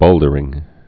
(bōldər-ĭng)